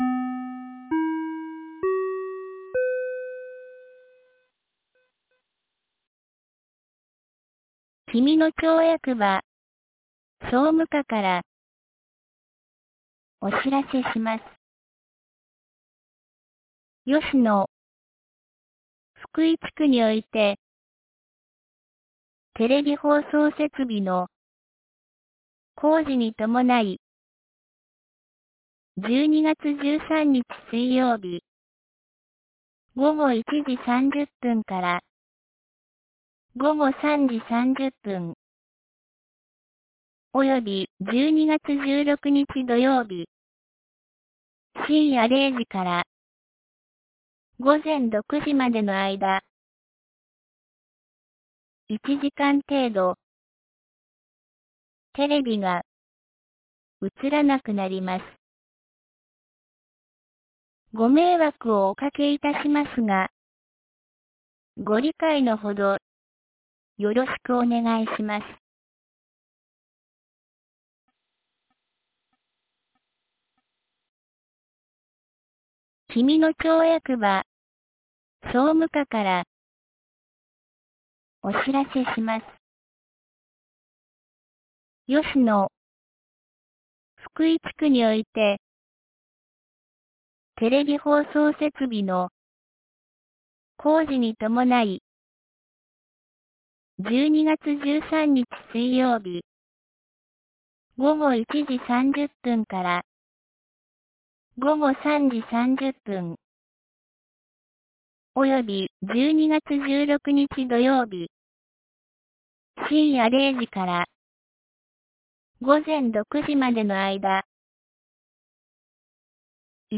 2023年12月11日 12時47分に、紀美野町より小川地区へ放送がありました。